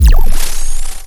sci-fi_electric_pulse_power_down_01.wav